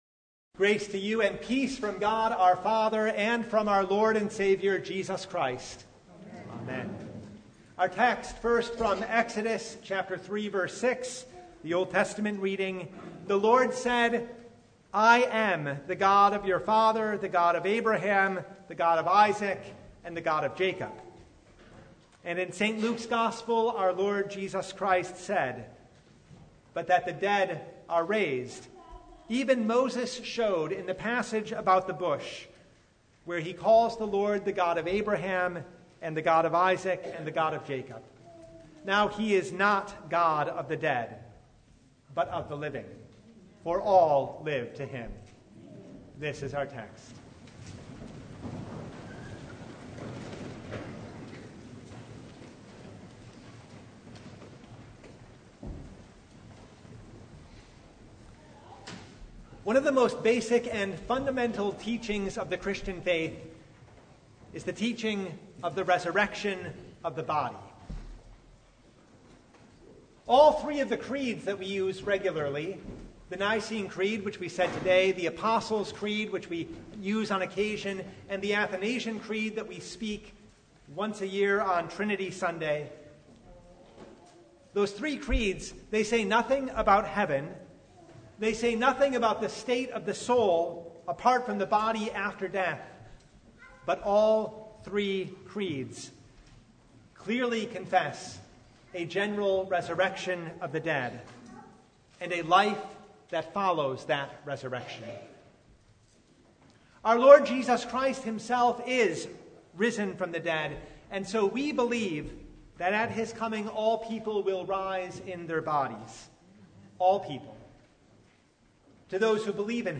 Luke 20:27–40 Service Type: Sunday There’s really no denying it